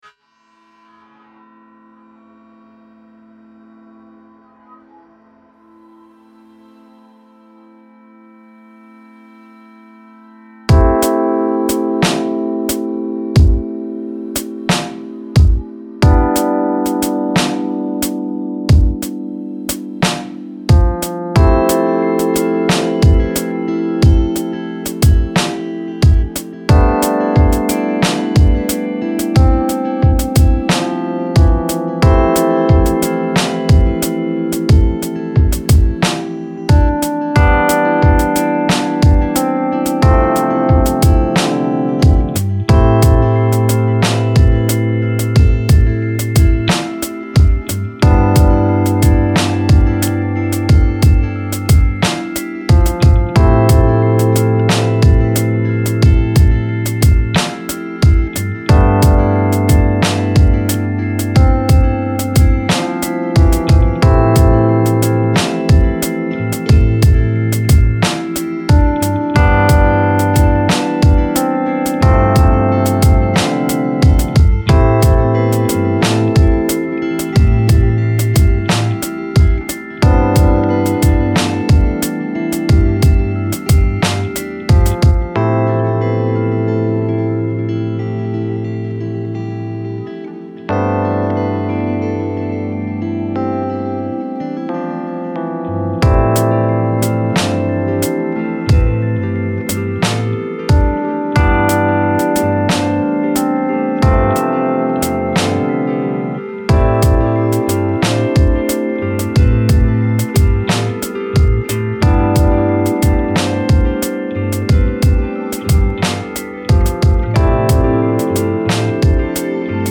An equal blend of organic recordings and electronic elements come together in this climbing, yet ambient track.